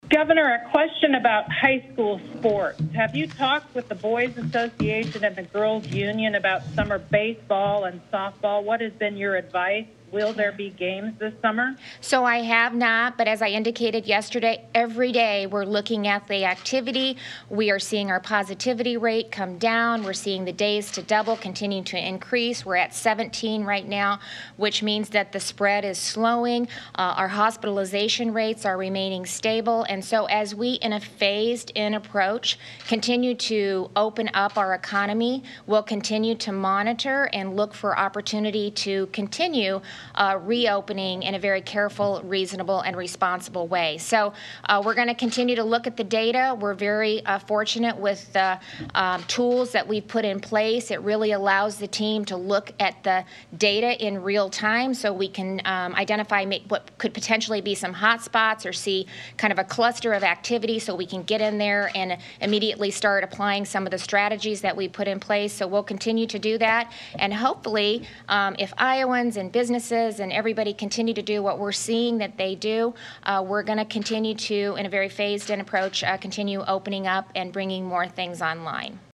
Reynolds was asked specifically about high school baseball and softball today during her daily news conference.
AUDIO of questions and the governor’s answer.